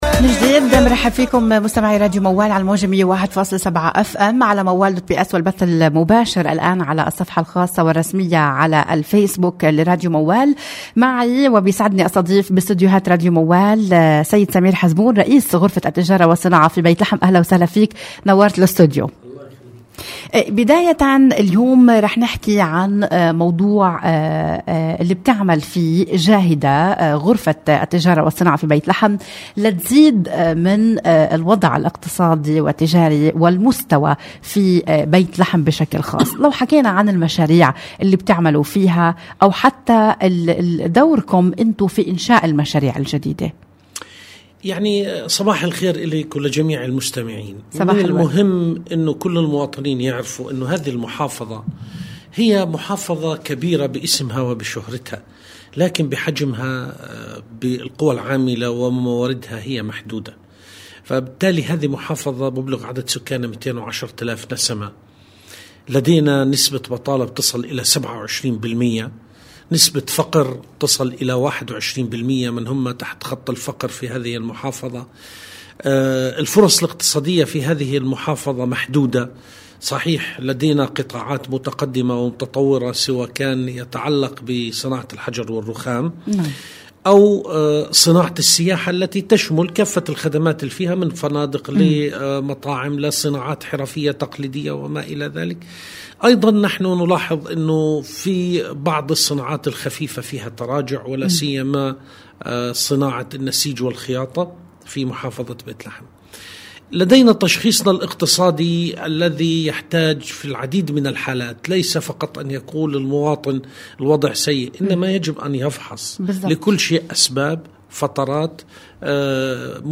المزيد من التفاصيل في هذا اللقاء: